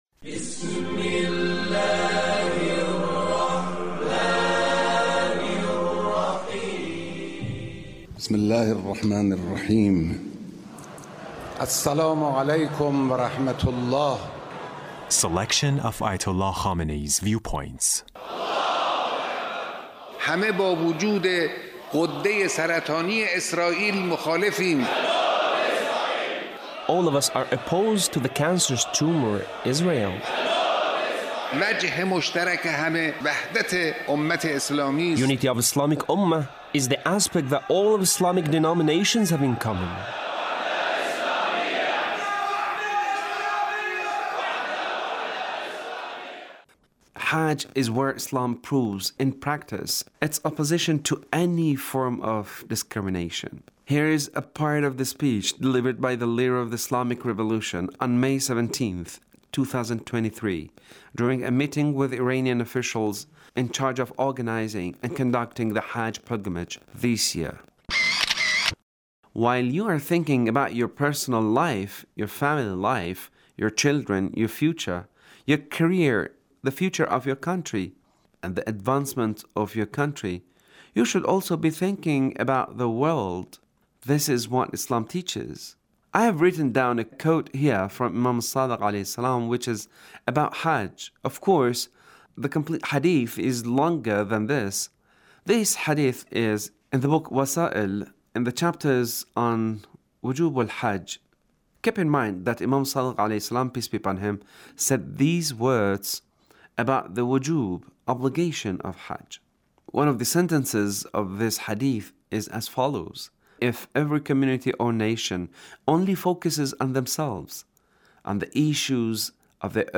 Leader's Speech (1732)
Leader's Speech in A Meeting with Iranian Commanders and Senior Military Officials